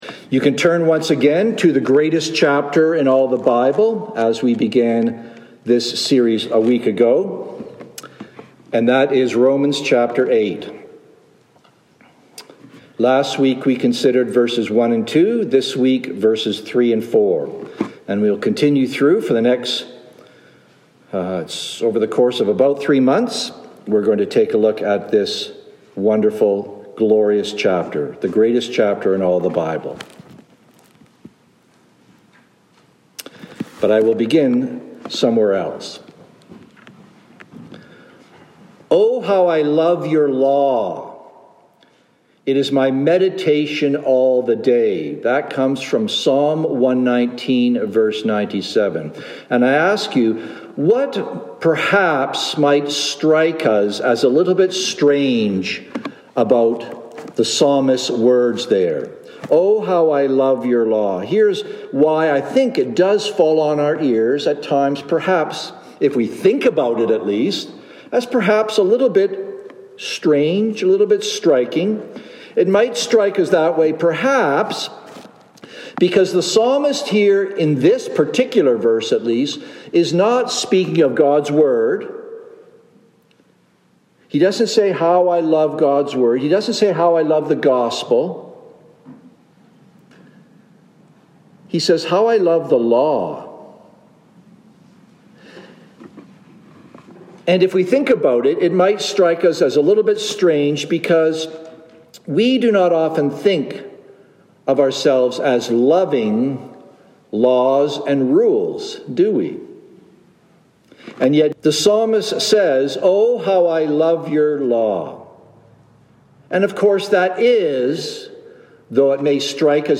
Download Download Reference Rom 8:3-4 Sermon Notes Mar19-23PM.docx From this series "Who Shall Separate?"